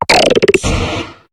Cri de Gamblast dans Pokémon HOME.